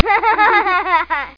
giggle.mp3